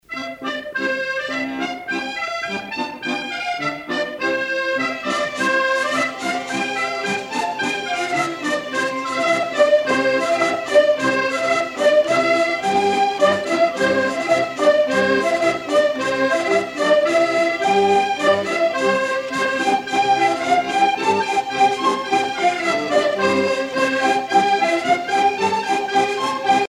danse : rondeau
Pièce musicale éditée